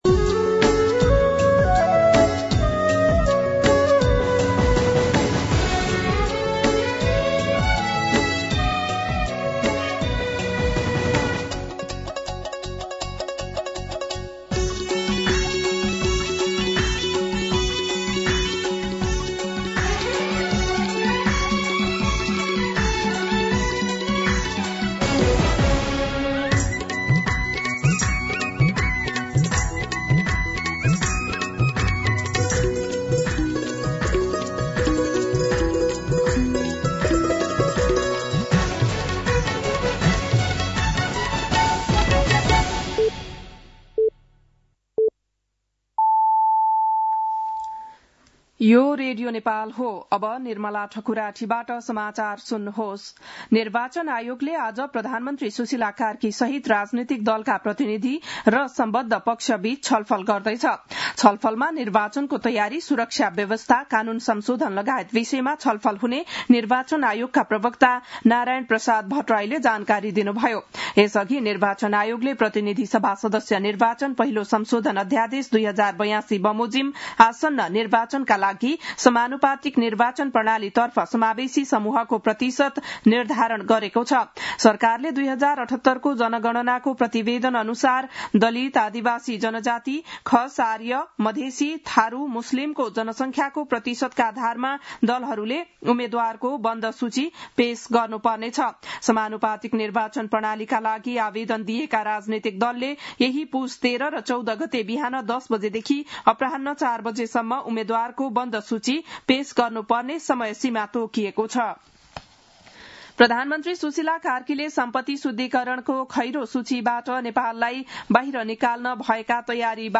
बिहान ११ बजेको नेपाली समाचार : १८ पुष , २०२६